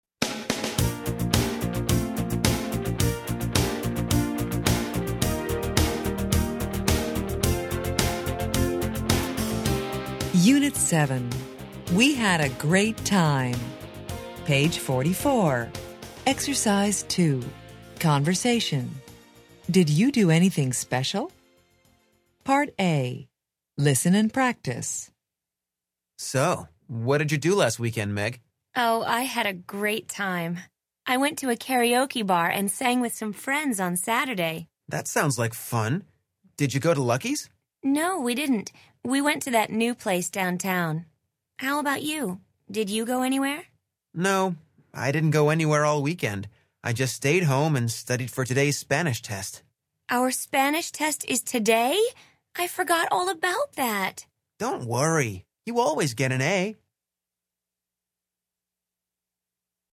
Interchange Third Edition Level 1 Unit 7 Ex 2 Conversation Track 20 Students Book Student Arcade Self Study Audio